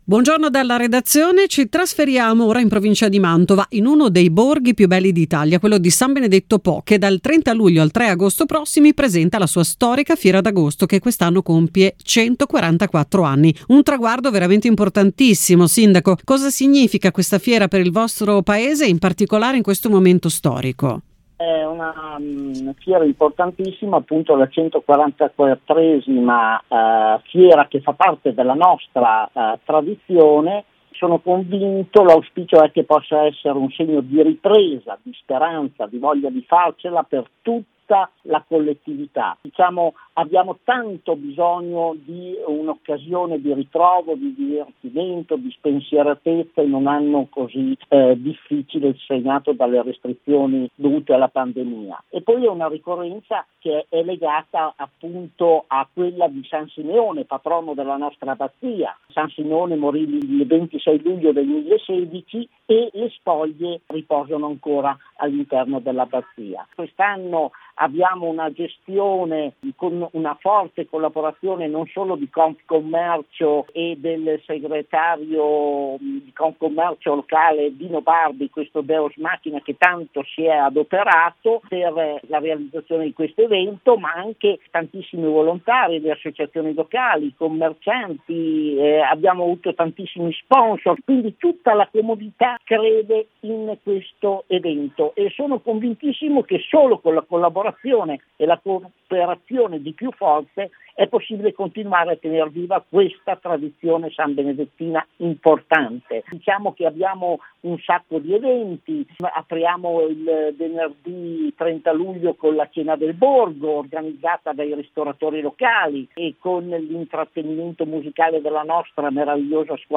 Al nostro micrfono il sindaco Roberto Lasagna ha sottolineato come questa edizione sia un segno di speranza e ripartenza per la comunità, da vivere in totale sicurezzae ha ringraziato tutti coloro che si sono messi a disposizione per riuscire a creare una manifestazione importante per la socialità,  con un occhio di riguardo all’eccellenza del Borgo, l’Abbazia del Polirone: